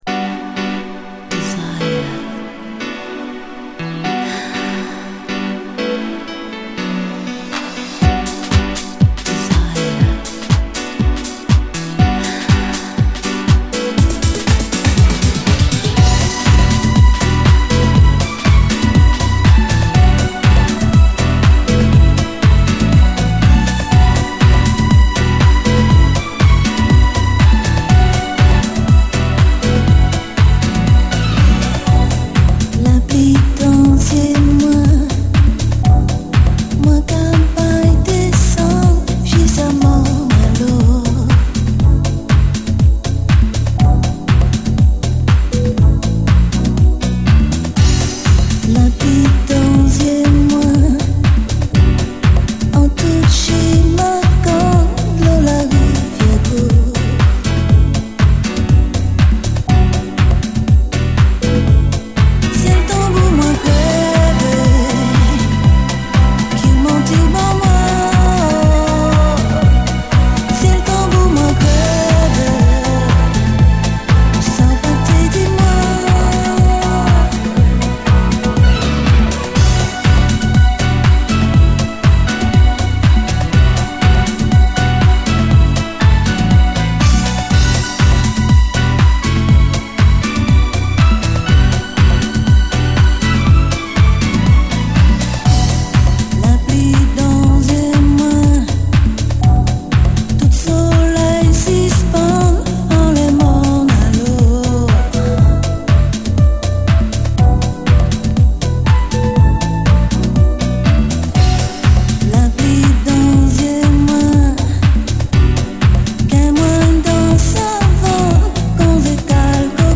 CARIBBEAN, DANCE
泣きのメロウ・ハウス！
スウィートでメロウでR&Bな流麗ハウス・トラック！